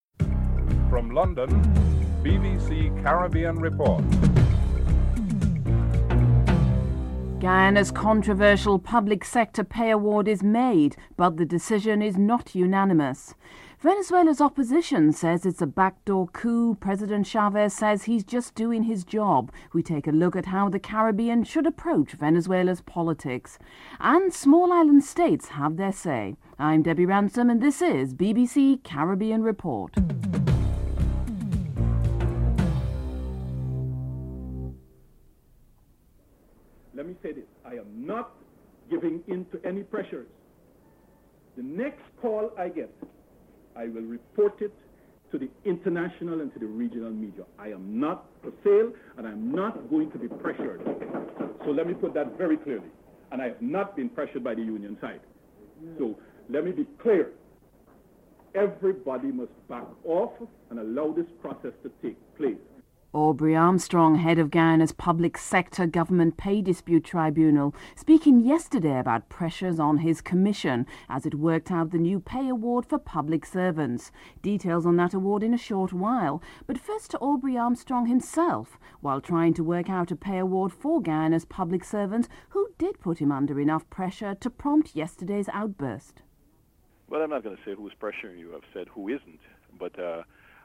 Antigua and Barbuda OAS Ambassador Lionel Hurst discusses the necessity of this new method of evaluation.
President Chavez speaks on the role of the Assembly.